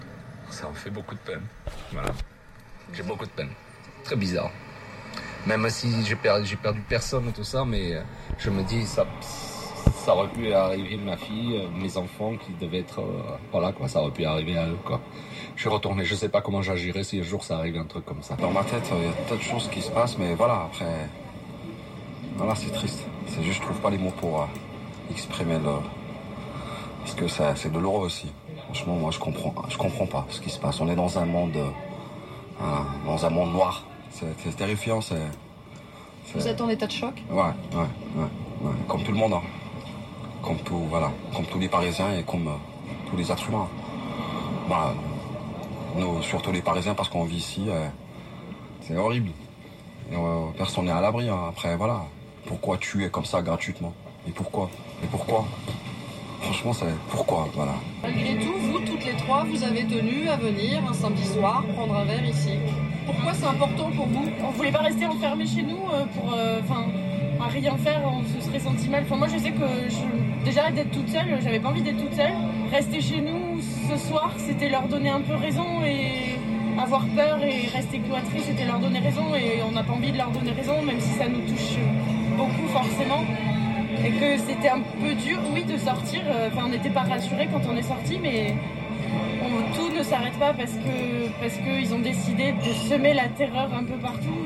Entendus ce matin à la radio, quelques échos tout simples, le jour d’après :